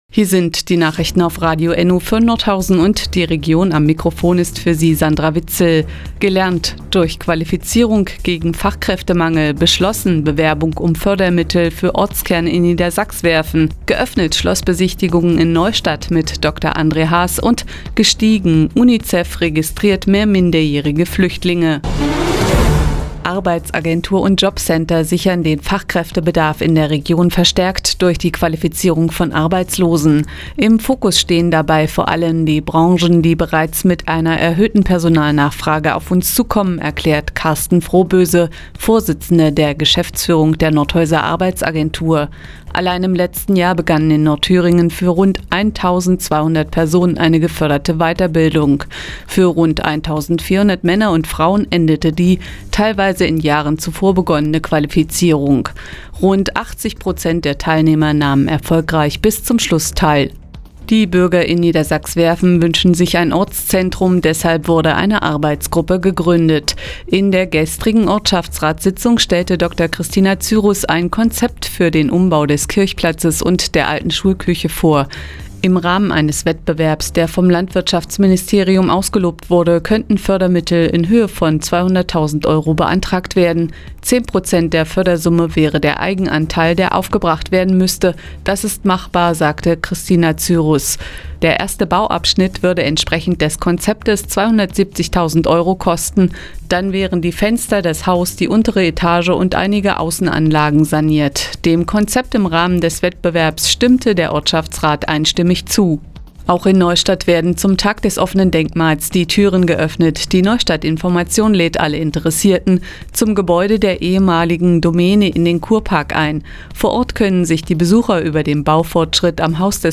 Mi, 17:00 Uhr 07.09.2016 Neues von Radio ENNO „Fazit des Tages“ Seit Jahren kooperieren die Nordthüringer Online-Zeitungen und das Nordhäuser Bürgerradio ENNO. Die tägliche Nachrichtensendung ist jetzt hier zu hören...